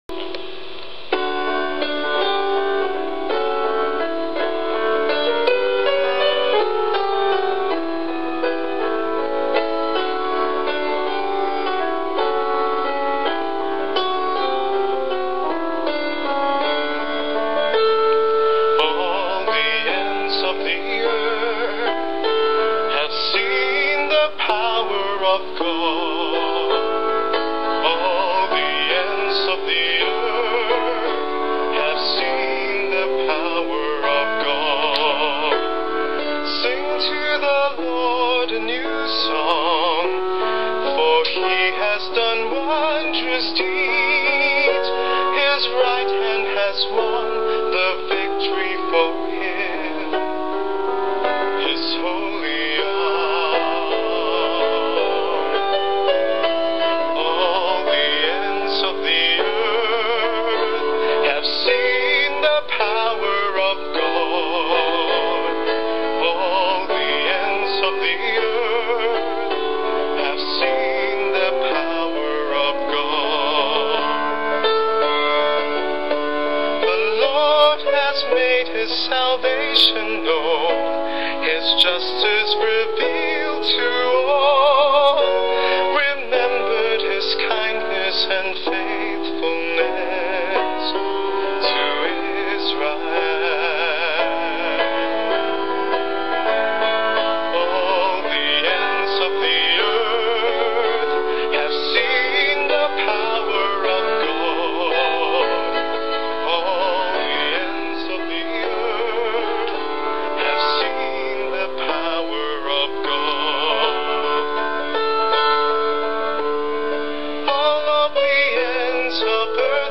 Christmas Day, 25 December
Psalm Gospel Acc